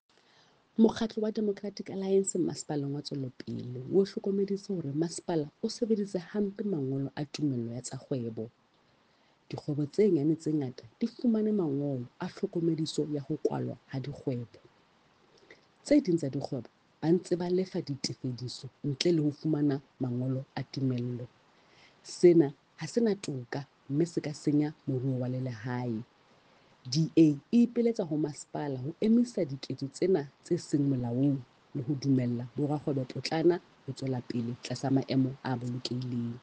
Sesotho soundbite by Cllr Mahalia Kose.